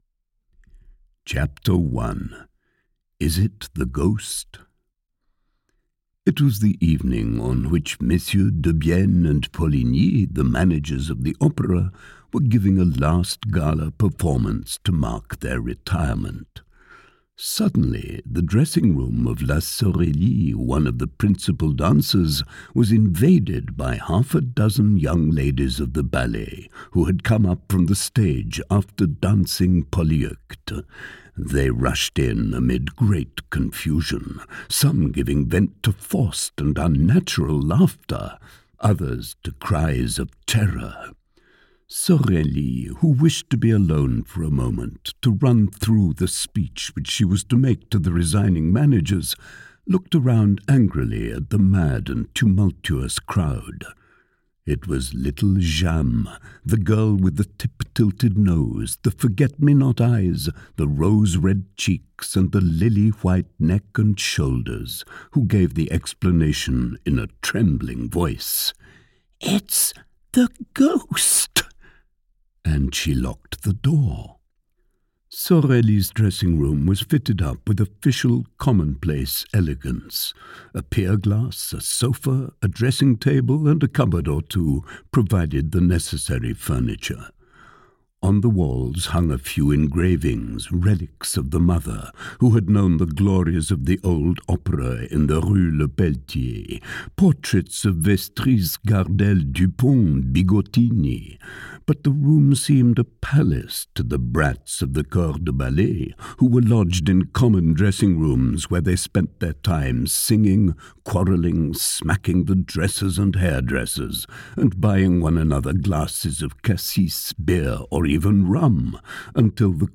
The Phantom of the Opera audiokniha
Ukázka z knihy